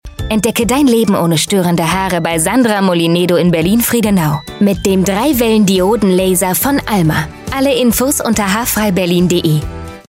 haarfreiberlin radiospot